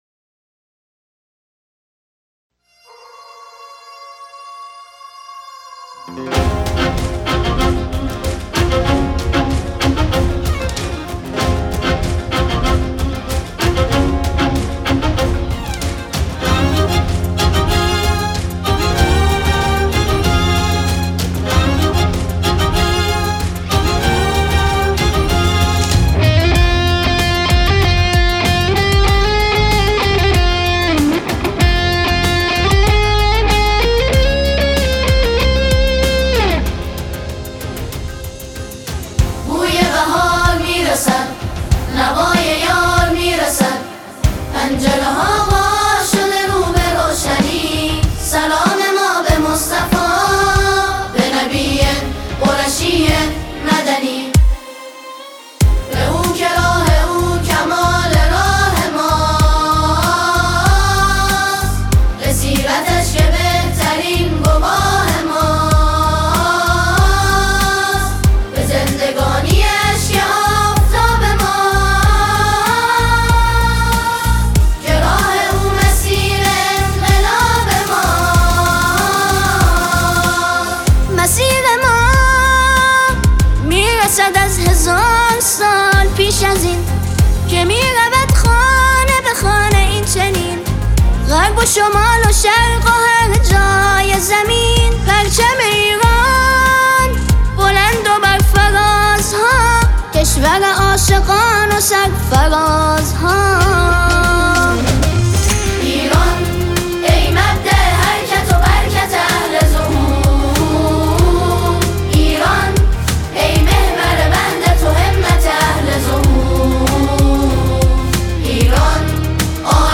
با اجرای گروه سرود صاحب الامر (عج)